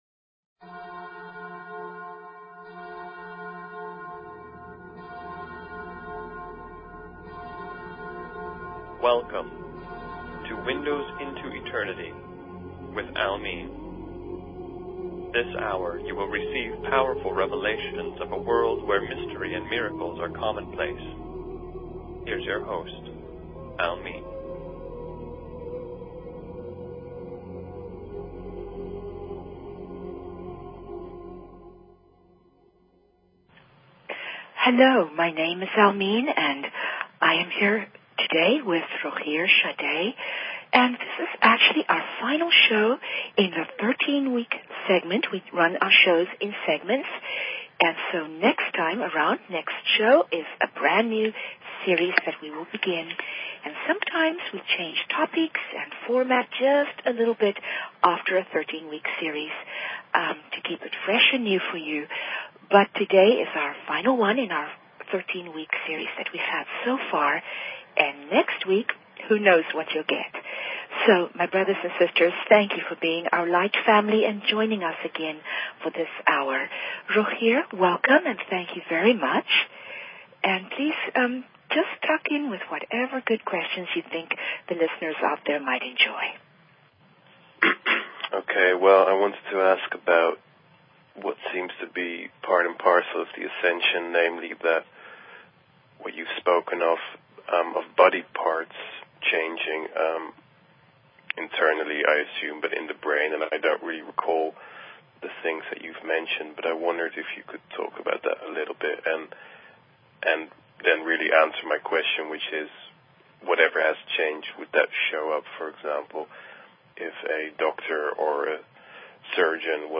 Show Headline Windows_Into_Eternity Show Sub Headline Courtesy of BBS Radio Q&A Spiritual-related Topics Windows Into Eternity Please consider subscribing to this talk show.